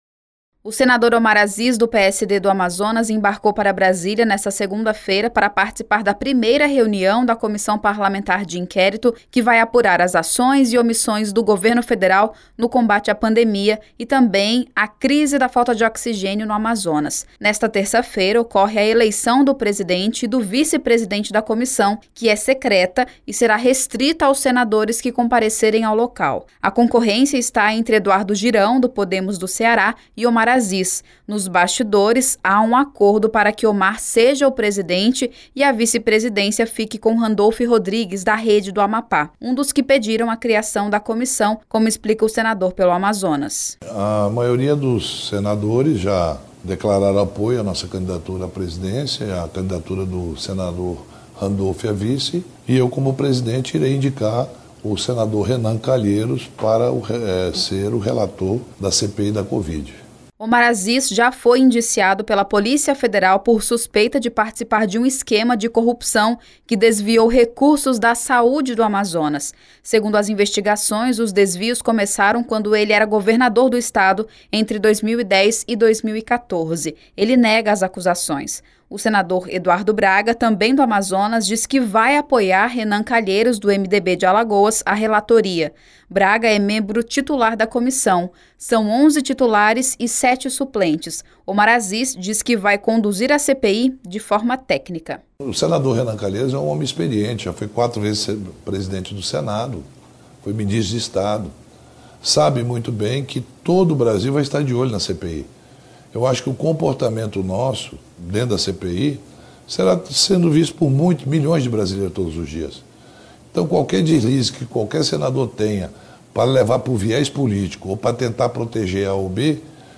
Entenda na reportagem: